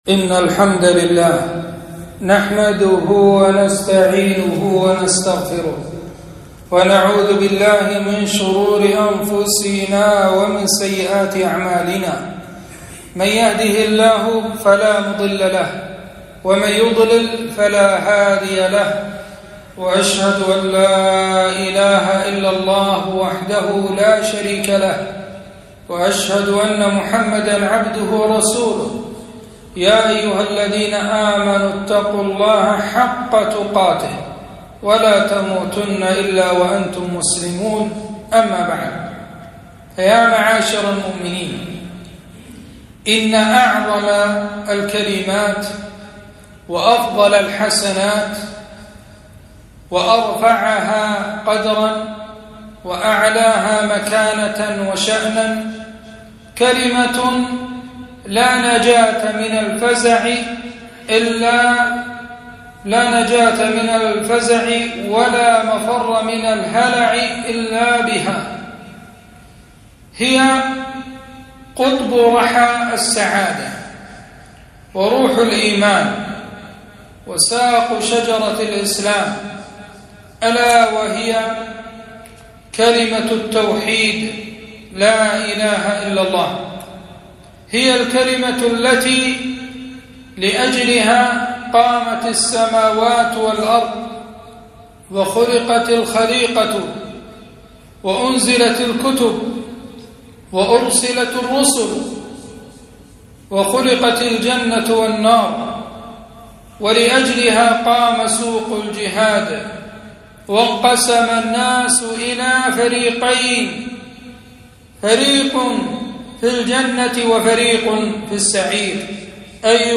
خطبة - التوحيد التوحيد يا عباد الله